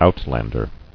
[out·land·er]